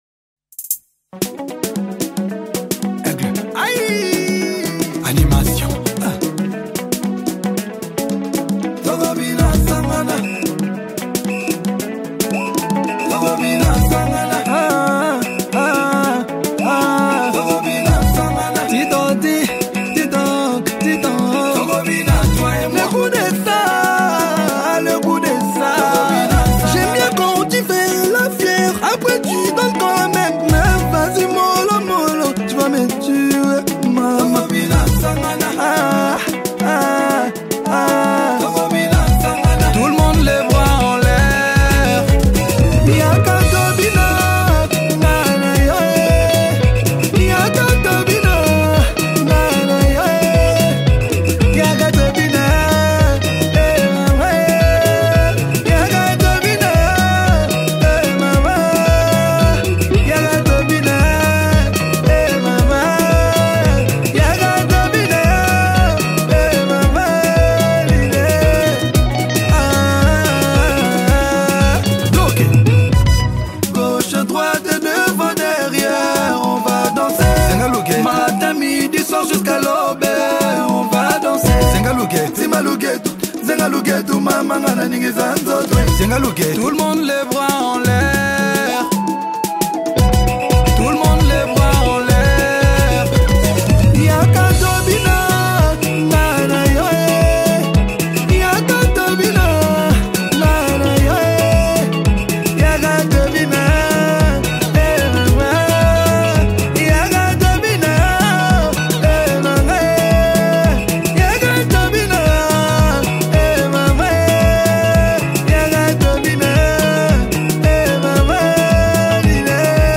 | Afro Congo